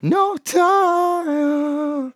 Categories: Vocals Tags: dry, english, LOFI VIBES, LYRICS, male, No, sample, TIMEHAH
MAN-LYRICS-FILLS-120bpm-Am-2.wav